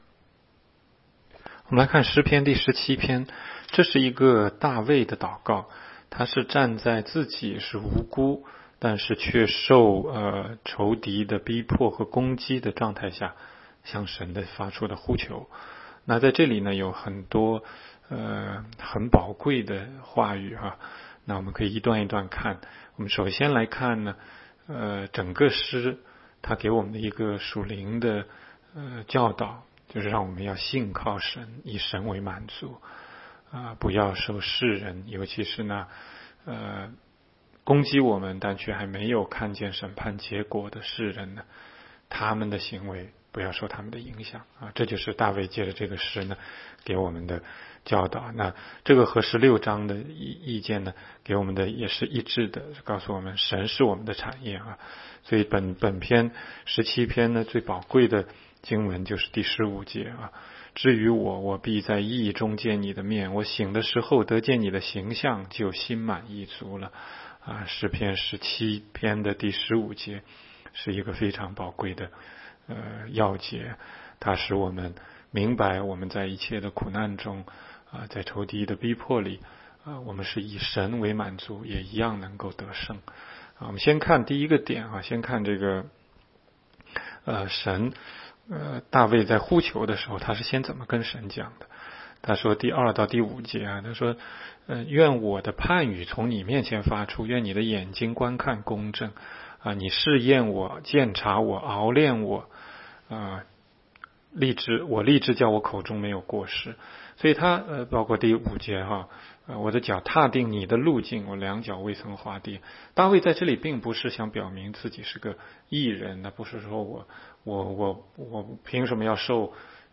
16街讲道录音 - 每日读经-《诗篇》17章